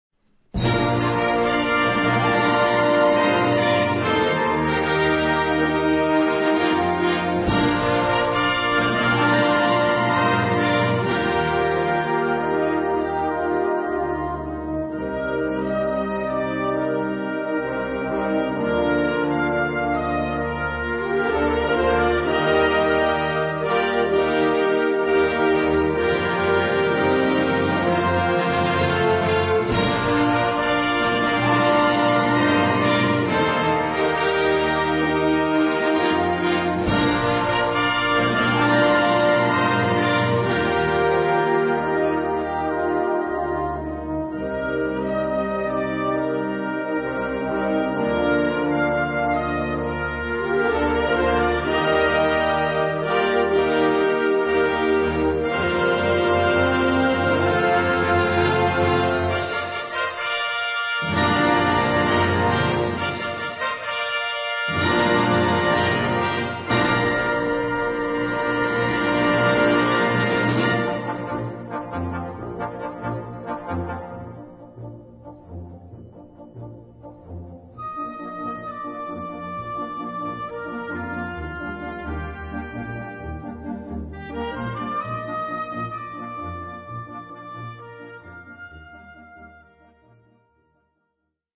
Catégorie Harmonie/Fanfare/Brass-band
Sous-catégorie Ouvertures (œuvres originales)
Instrumentation Ha (orchestre d'harmonie)